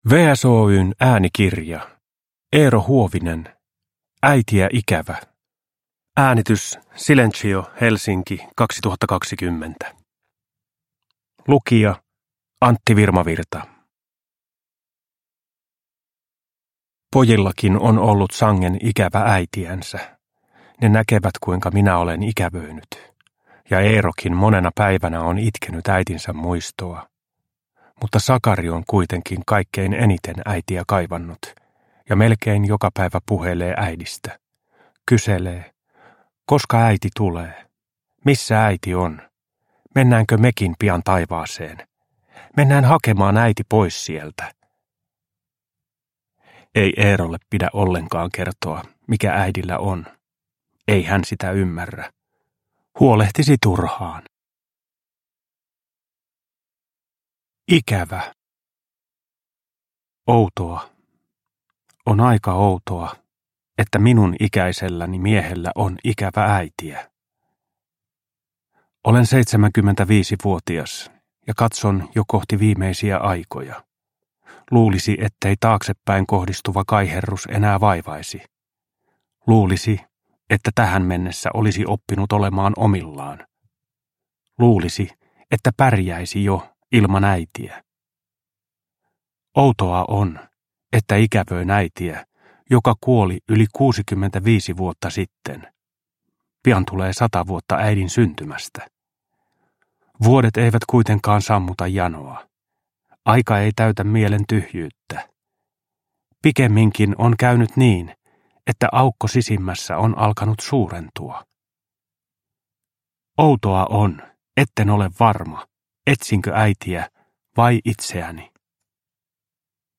Äitiä ikävä – Ljudbok – Laddas ner
Uppläsare: Antti Virmavirta